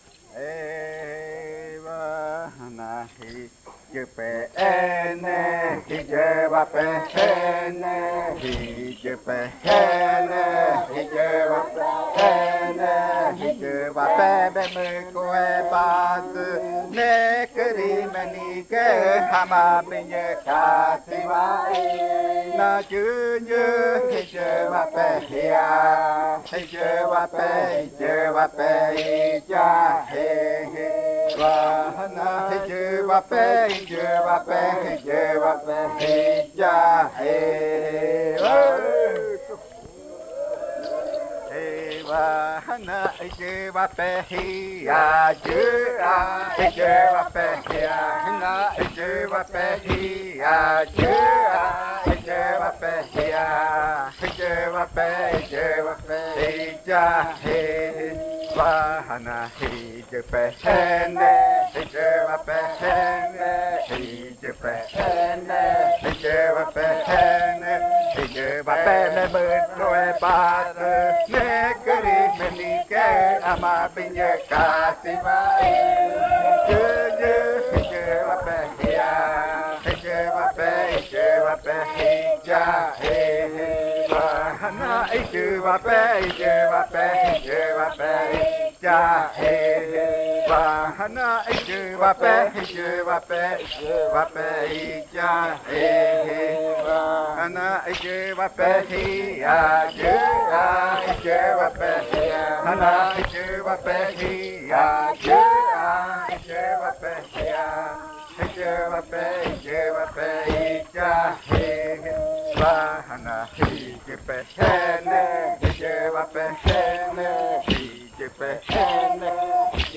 43. Baile de nombramiento. Canto n°5
Puerto Remanso del Tigre, departamento de Amazonas, Colombia